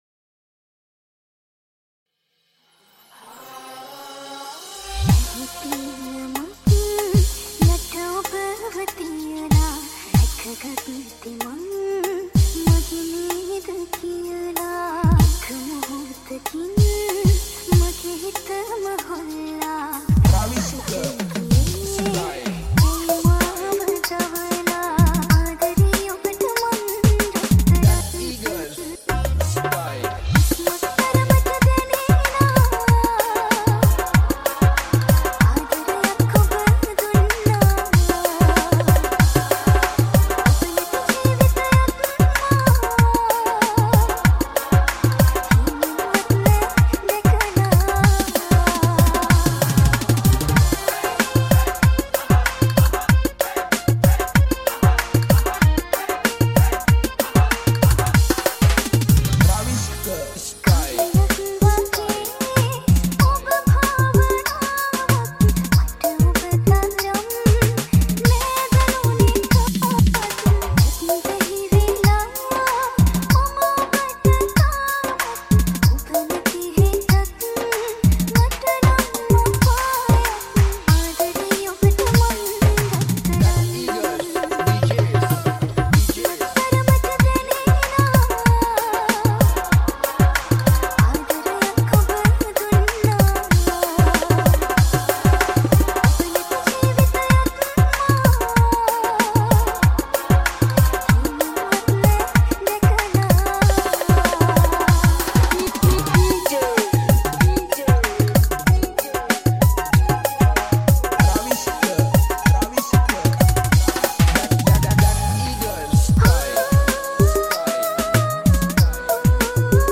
High quality Sri Lankan remix MP3 (3.4).
Remix